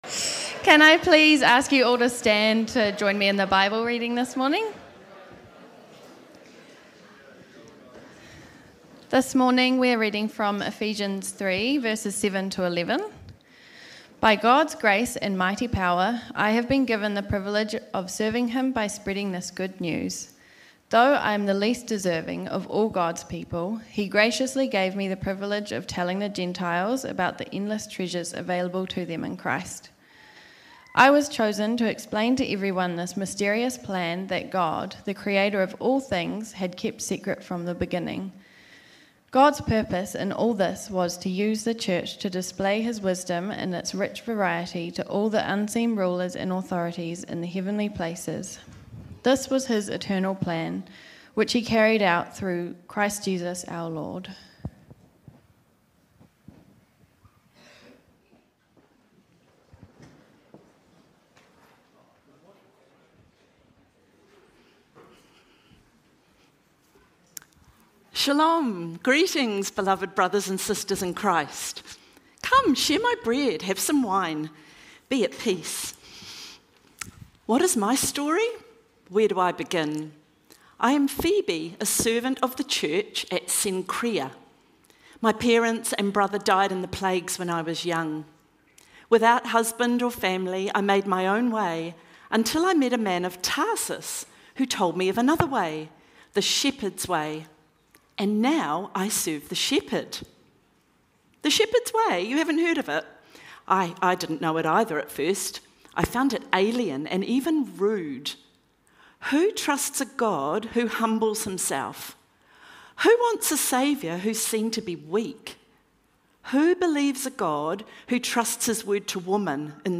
Sermons | Titirangi Baptist Church
As we bring these stories to life through the synergy of preaching and dramatic performance, we pray that it stirs a desire in all of us to extend the life-changing gospel of Jesus to those around us, fostering a community rooted in compassion and action. Today we are looking at Romans 16:1-2 - when Jesus asks you to do the unconscionable for the undesirable.